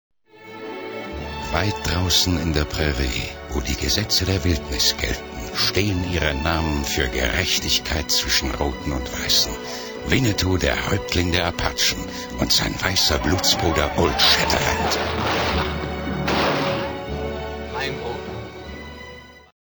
Die dunkle, rauchig-warme Stimme war das große Kapital von Wolfgang Hess und kam auch in unzähligen Dokumentationen und Fernsehreportagen als Erzählstimme zum Einsatz.
H Ö R B E I S P I E L E – in der finalen Tonmischung: